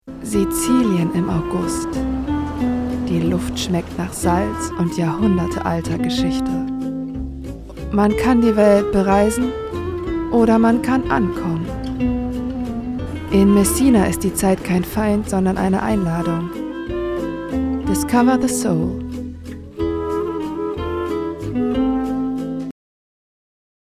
markant
Mittel minus (25-45)
Russian, Eastern European
Comment (Kommentar), Narrative